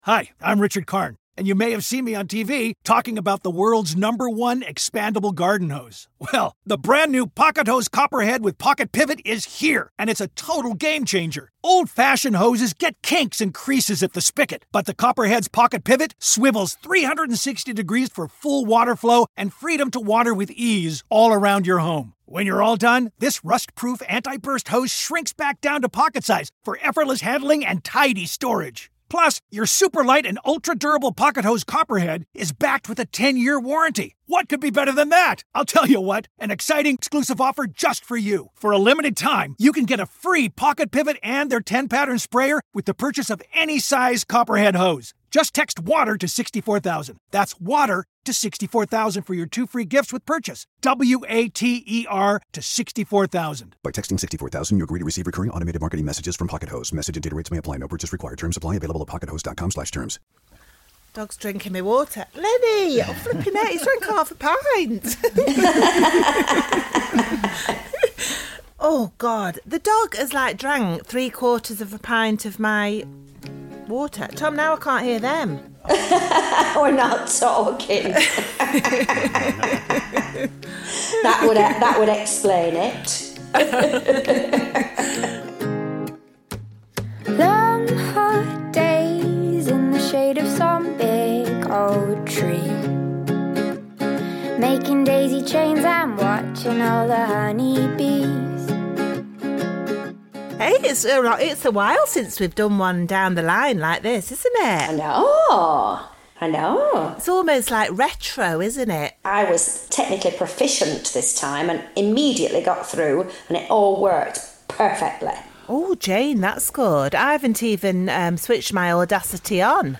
Esther's been stuck at home this week so Jane gives her a call to cheer her up. Along the way there's a catch up from Chelsea, scattering ashes, what the bees have been up to and of course, why you should always have a spare lawnmower.
Queen Bees is a Hat Trick Podcast created and presented by Jane Horrocks and Esther Coles